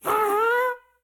Minecraft Version Minecraft Version snapshot Latest Release | Latest Snapshot snapshot / assets / minecraft / sounds / mob / happy_ghast / ambient5.ogg Compare With Compare With Latest Release | Latest Snapshot